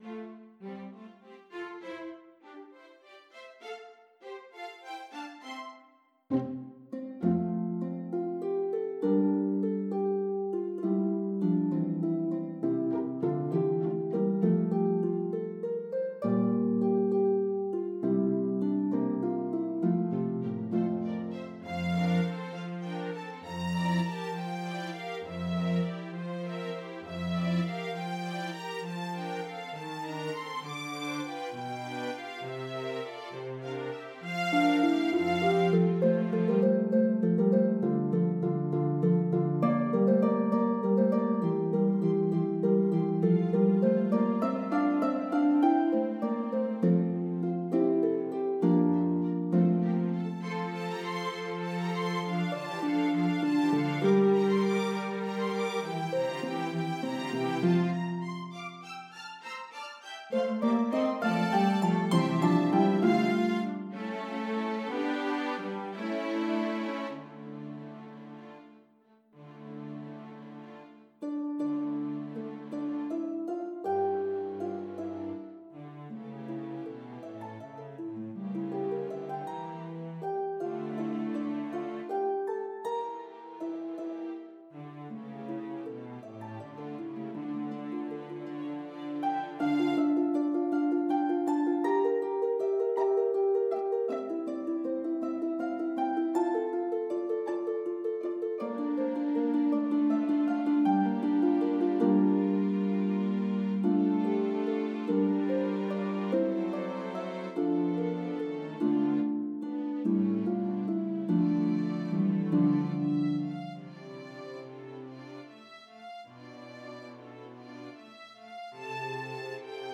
The traditional Irish
for lever or pedal harp and string quartet